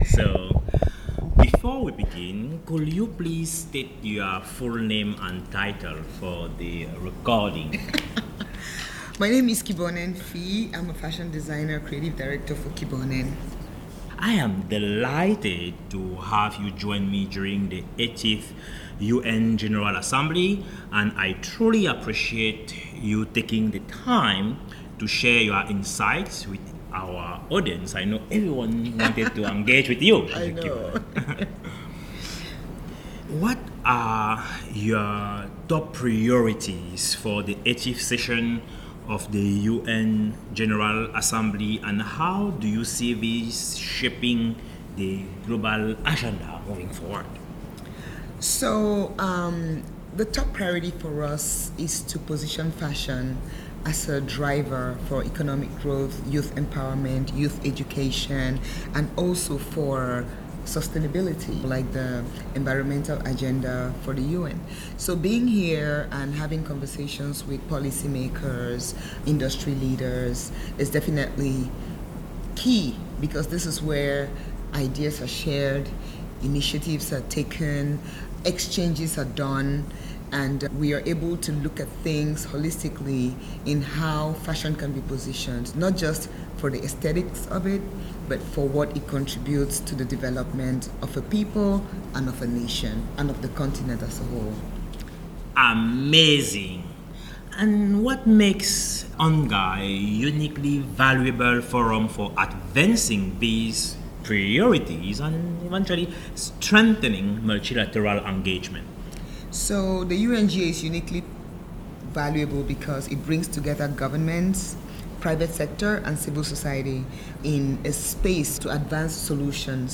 Foresight Africa podcast at UNGA 2025